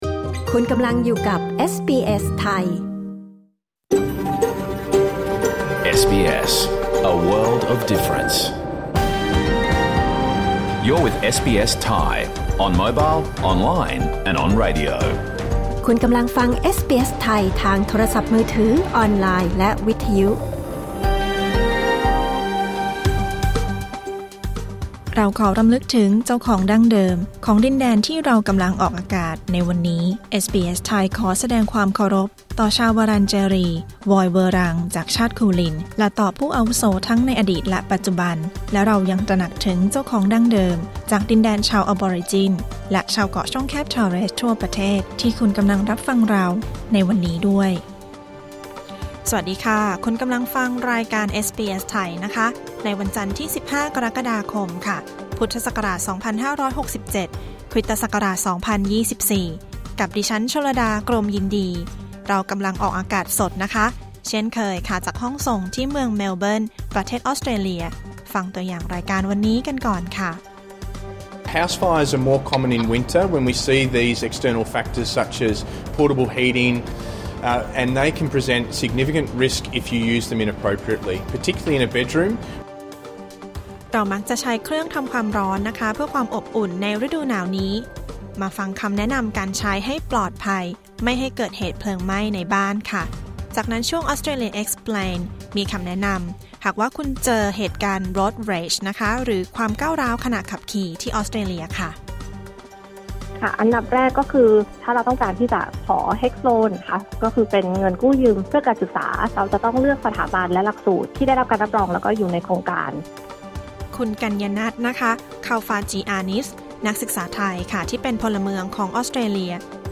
รายการสด 15 กรกฎาคม 2567